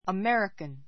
American 小 əmérikən ア メ リカン 形容詞 アメリカの ; アメリカ人の , アメリカ人で an American boy an American boy アメリカの少年 He is American.